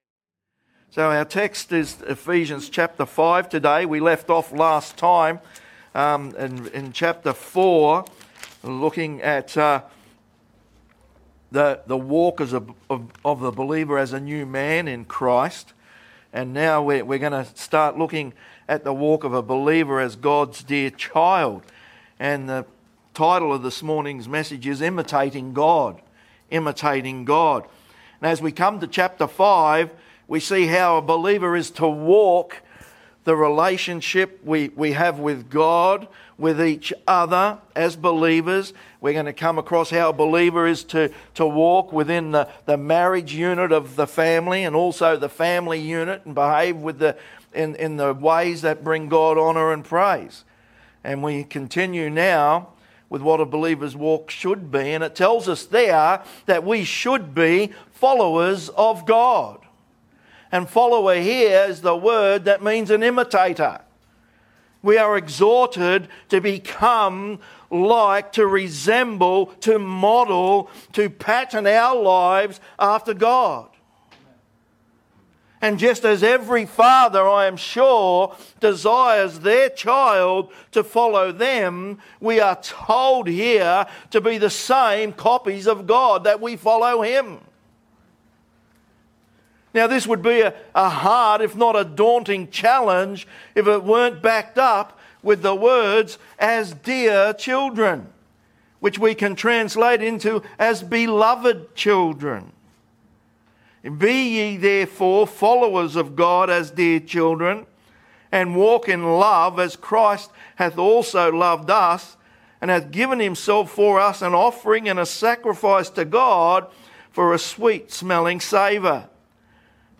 Bible Baptist Church of South East Qld Believers Position in the Grace of God - Imitating God Aug 24 2025 | 00:40:20 1x 00:00 / 00:40:20 Subscribe Share Spotify RSS Feed Share Link Embed